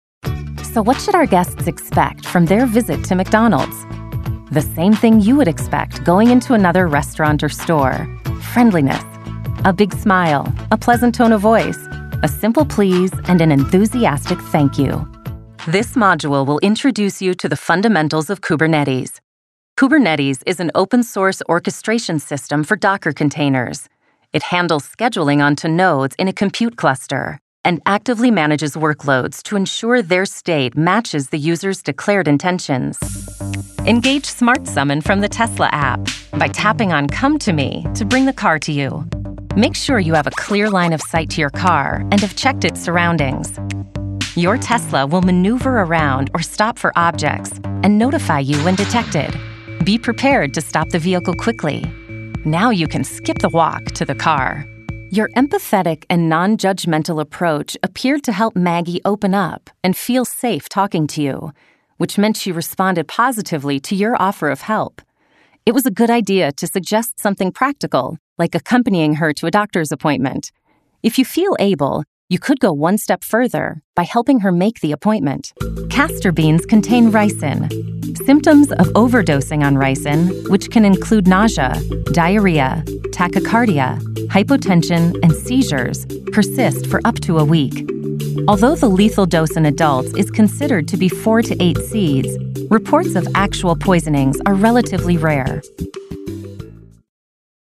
Inglés (Estados Unidos)
Amistoso
Conversacional
Brillante